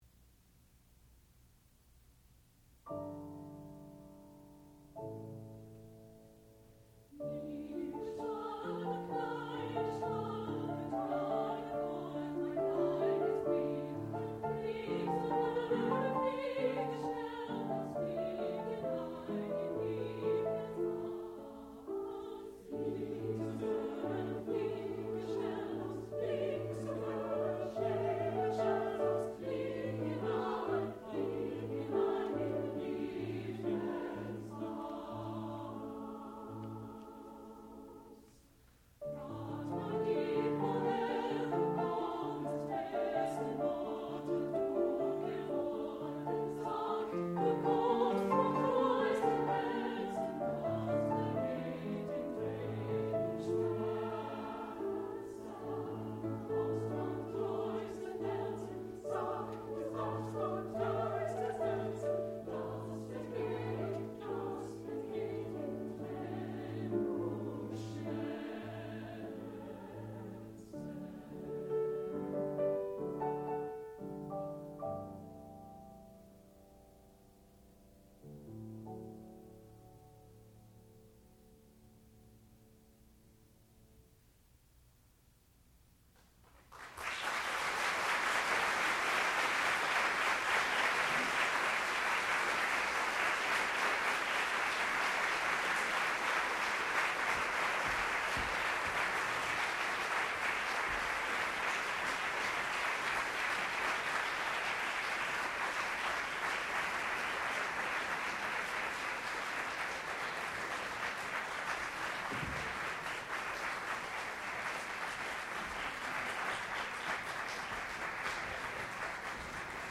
classical music